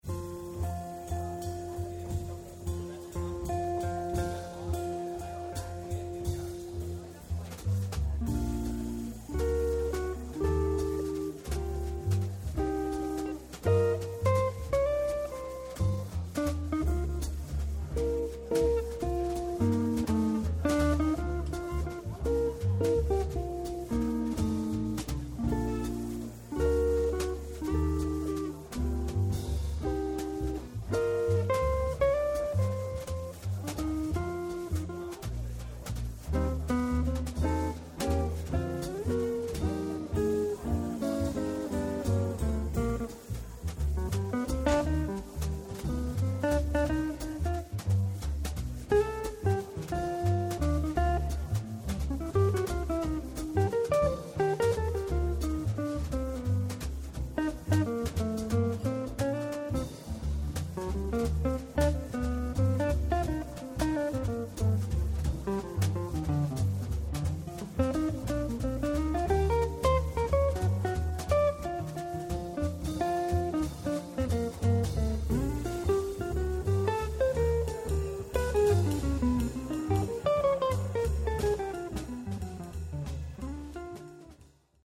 • The quintessential jazz trio
South East Instrumental Jazz Trio